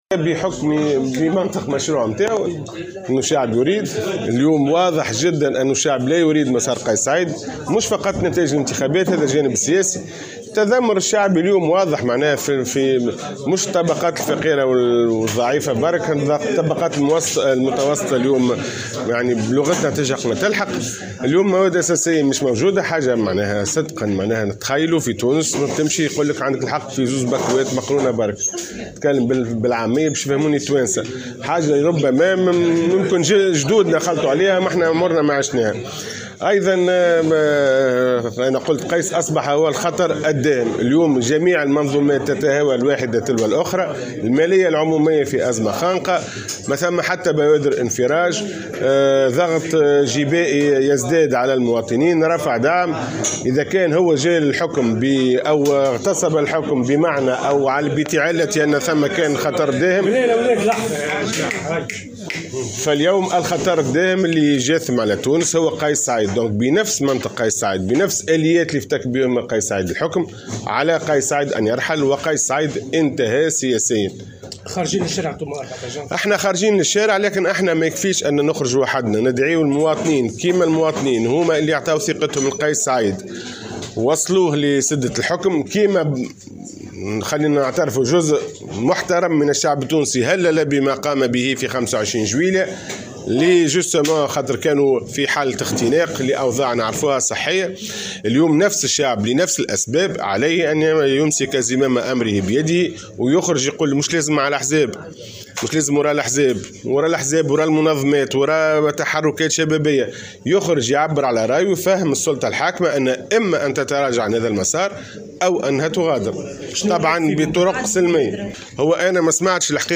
خلال ندوة صحفية انعقدت بمقر الحزب بالعاصمة